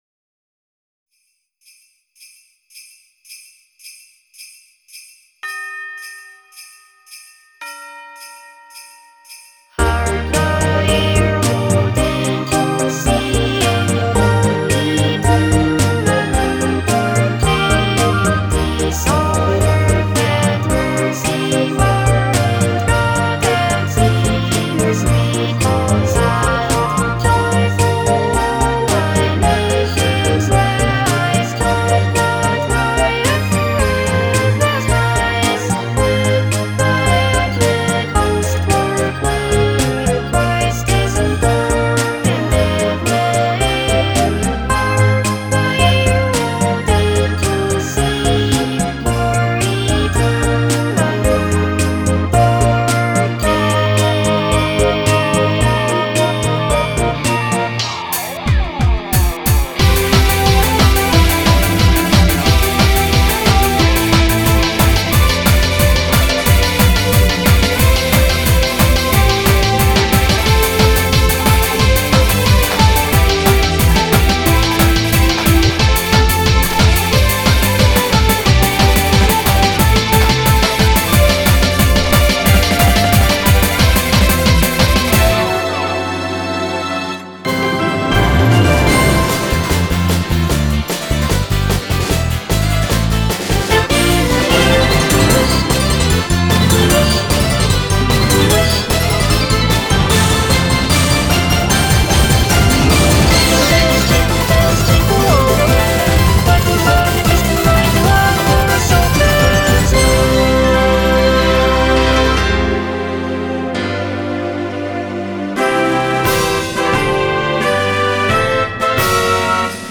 BPM70-180
Audio QualityPerfect (High Quality)
Genre: Xmas PRESENTS. It's a Christmas medley